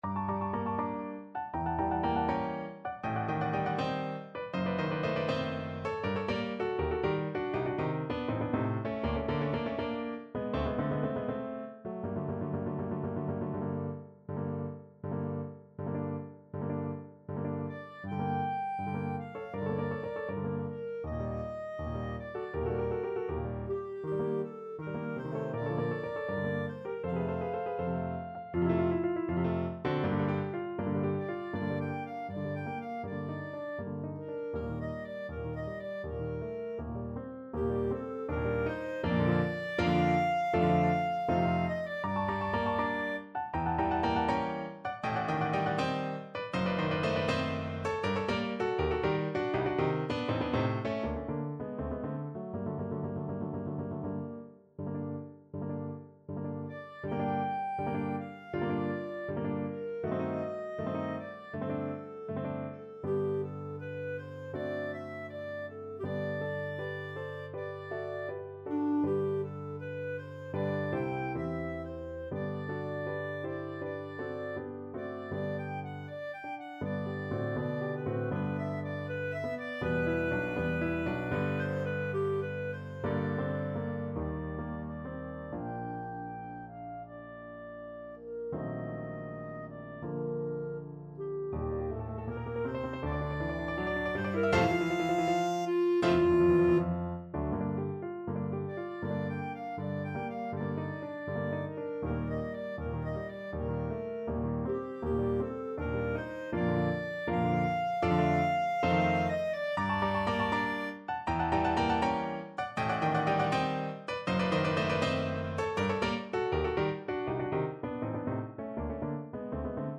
Clarinet
G minor (Sounding Pitch) A minor (Clarinet in Bb) (View more G minor Music for Clarinet )
4/4 (View more 4/4 Music)
~ = 100 Moderato =80
Classical (View more Classical Clarinet Music)